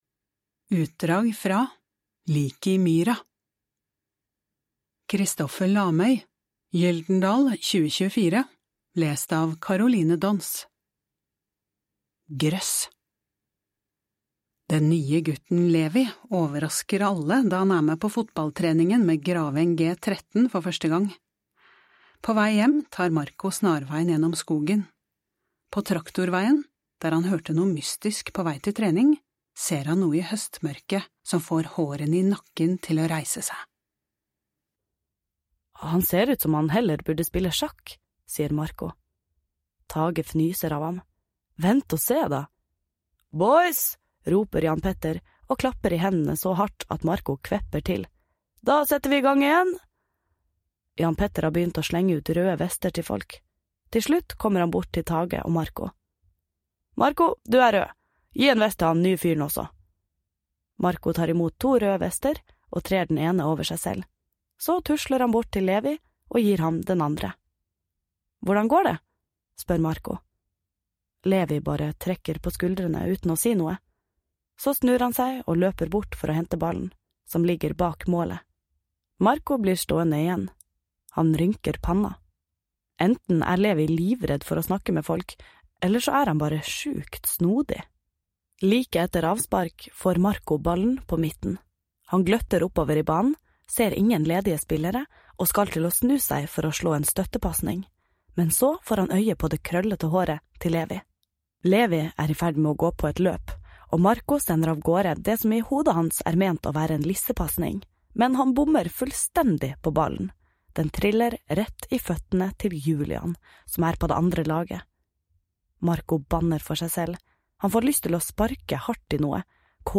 Lån hele lydboka fra Tibi! Den nye gutten, Levi, overrasker alle da han er med på fotballtreningen med Graveng G13 for første gang.